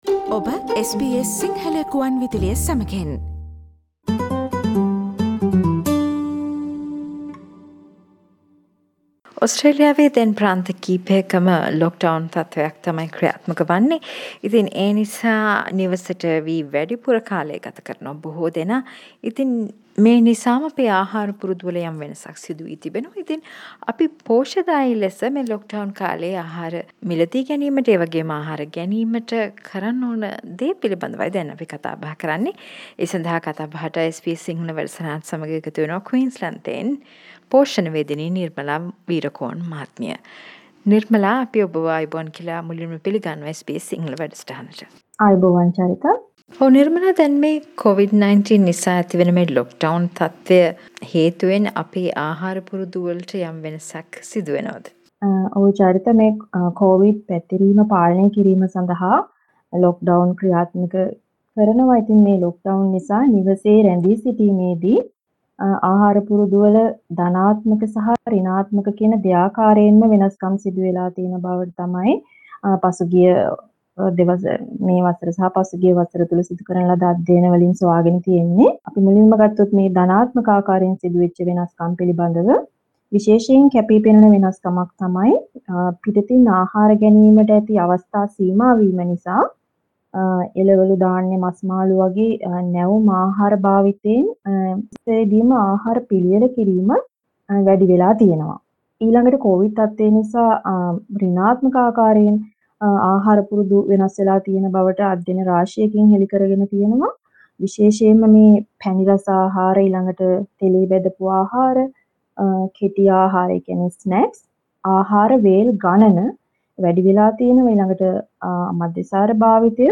SBS සිංහල සිදු කල පිලිසදරට සවන් දෙන්න